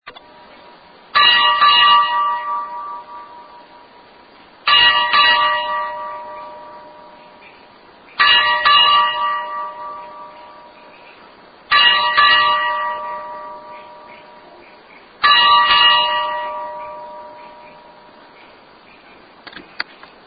Double Ring (Ding – Dong)